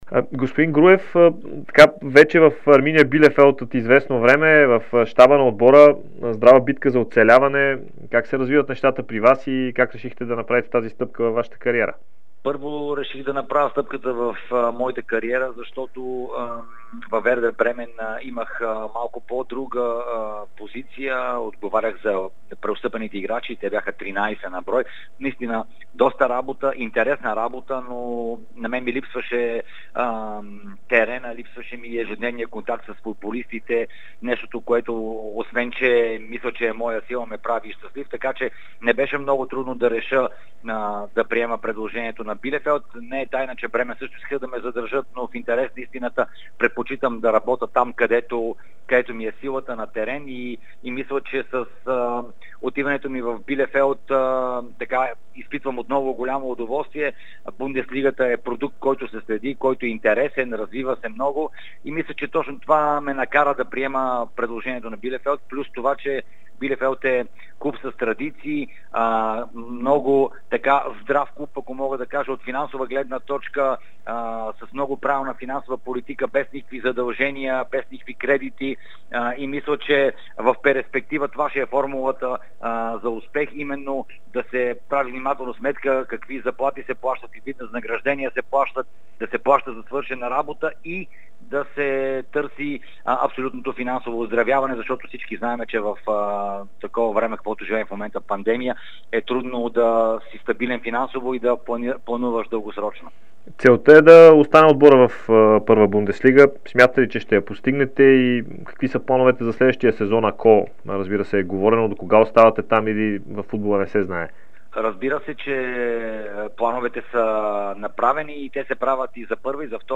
Българският специалист Илия Груев даде специално интервю за Дарик радио и dsport, след като бе назначен за помощник-треньор на Арминия Билефелд. Той заяви, че е направил стъпката в кариерата си, въпреки че от Вердер Бремен са пожелали да го задържат. Груев заяви, че темата за Суперлигата не е била толкова коментирана в Германия, защото местните тимове категорично отказаха да вземат участие в проекта.